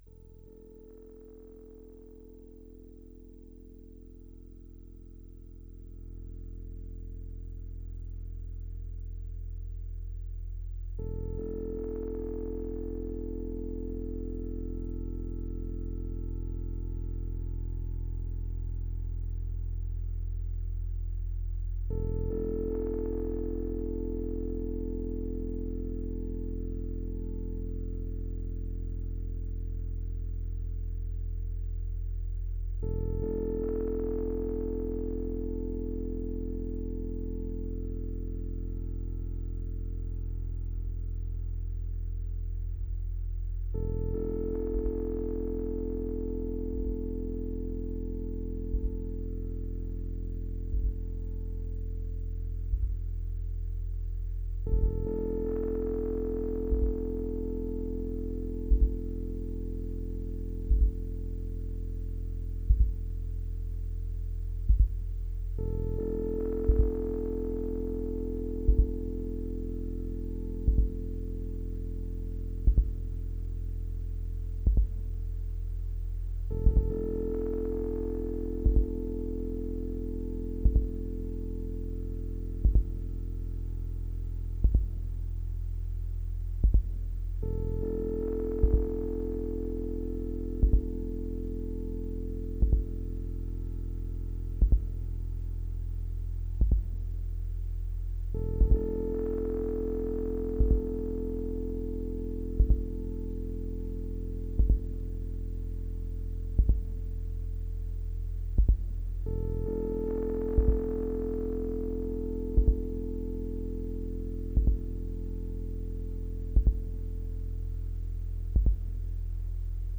Improvisé à partir de sons préexistants..